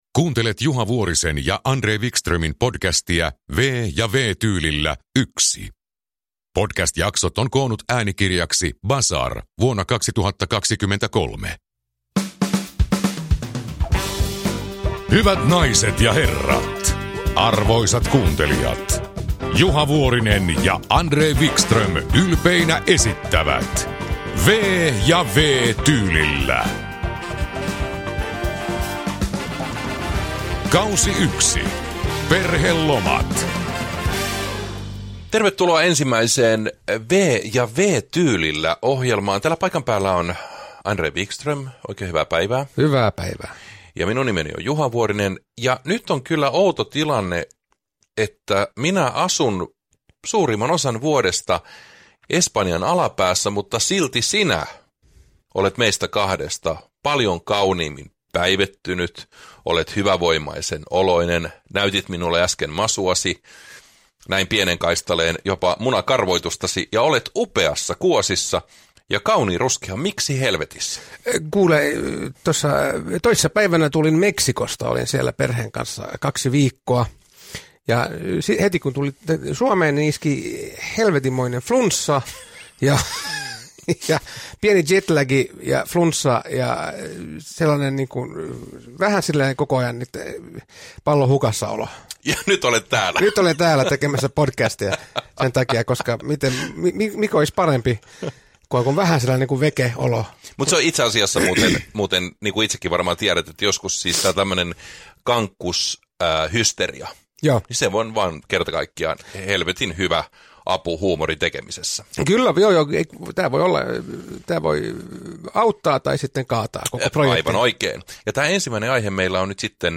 V- ja W-tyylillä K1 – Ljudbok
Uppläsare: Juha Vuorinen, André Wickström